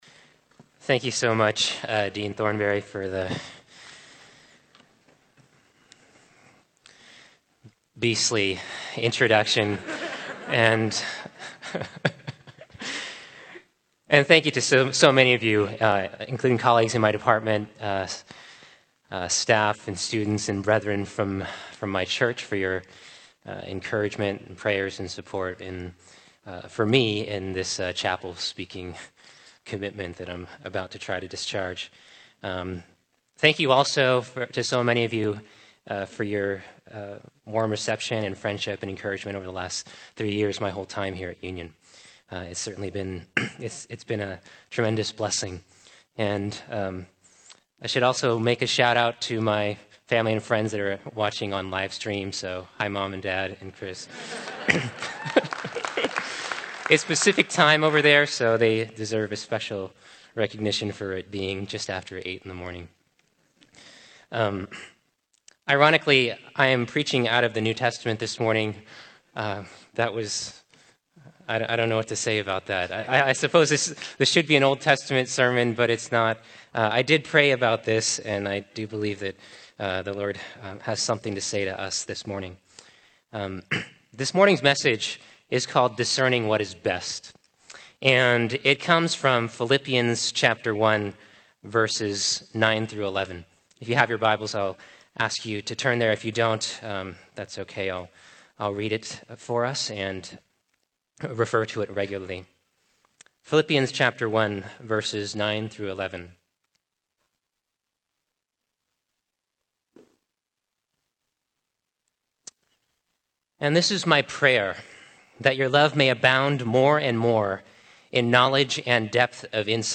Chapels